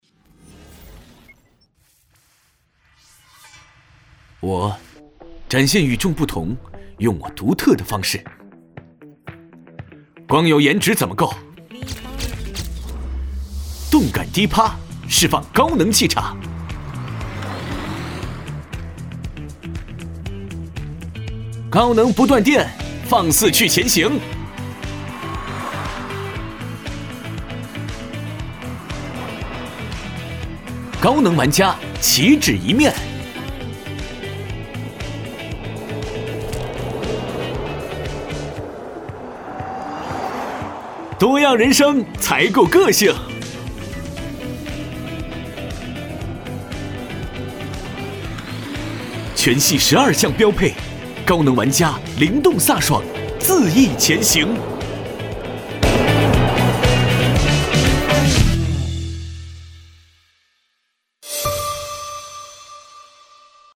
男国语332
【广告】动感汽车广告